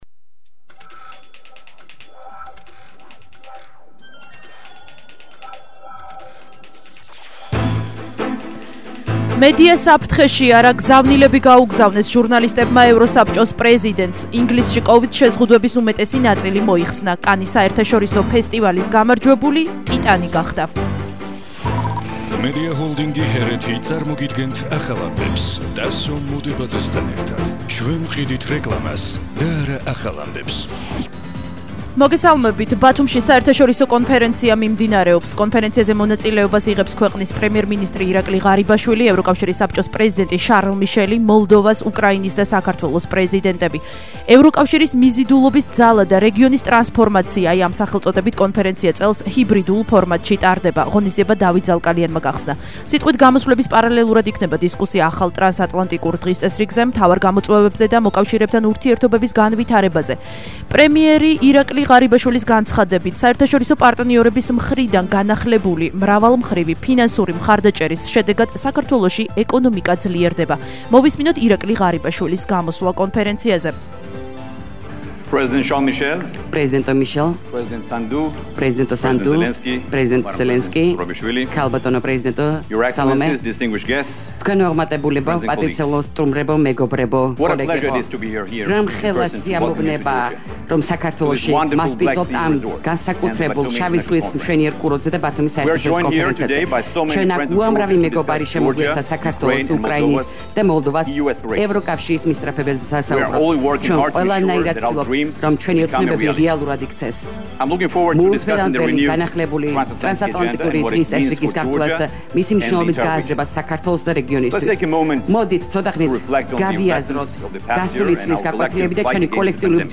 ახალი ამბები 17:00 საათზე –19/07/21 – HeretiFM